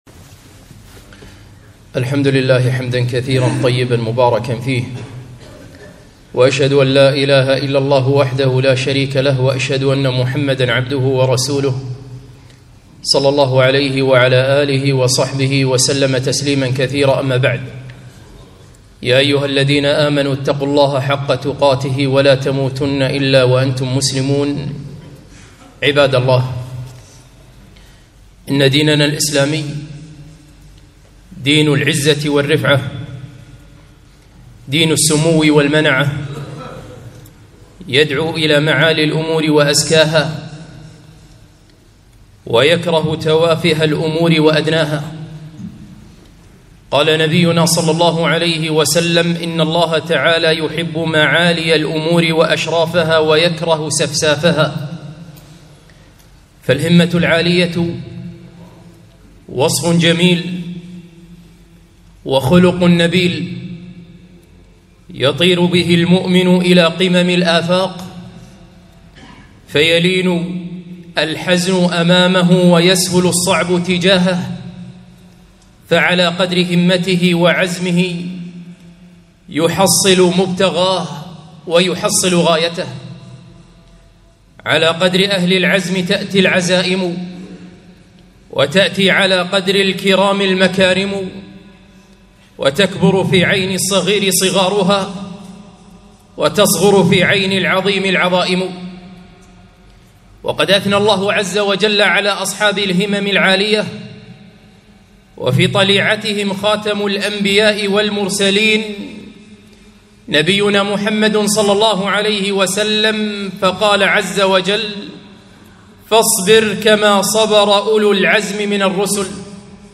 خطبة - هل أنت من أصحاب الهمة العالية؟